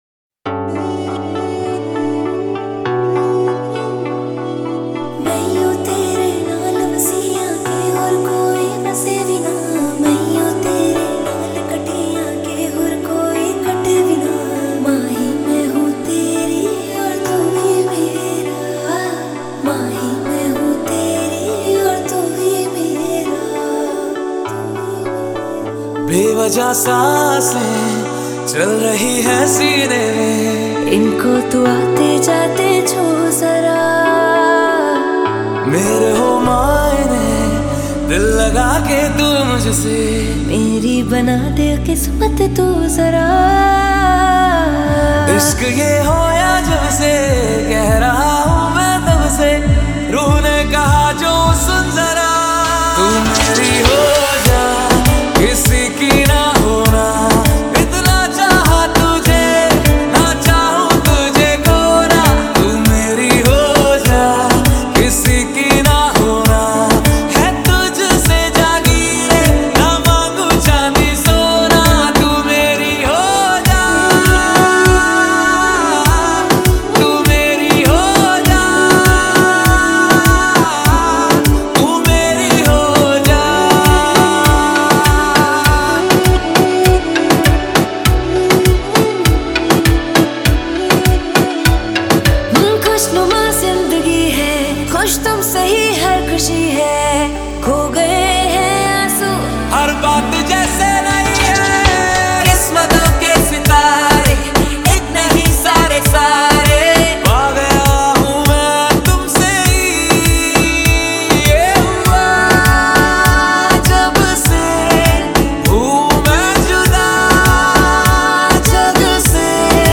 INDIPOP MP3 Songs
IndiPop Music Album